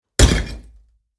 This category features Crumbling and cracking elements, Large stones impacts and much more!
Building-blocks-dropped-on-a-heavy-rock-4.mp3